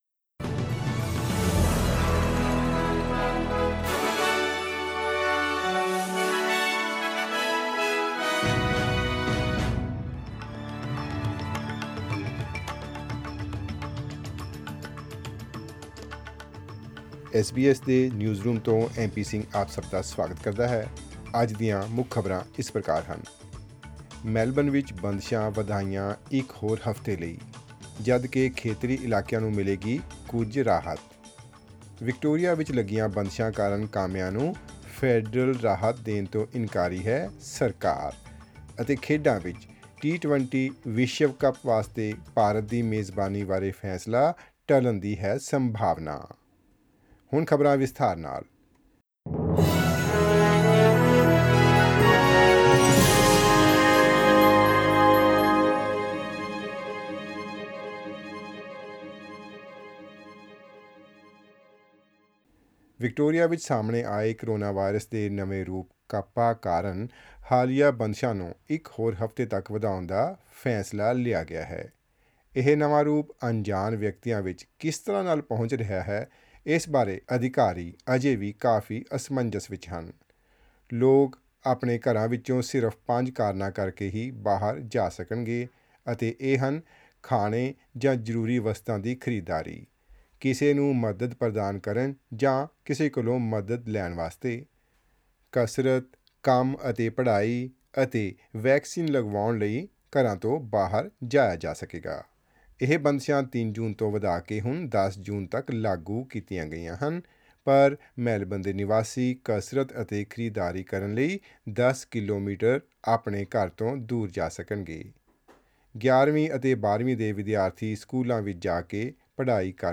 Listen to the latest news headlines in Australia from SBS Punjabi radio.
Click on the player at the top of the page to listen to the news bulletin in Punjabi.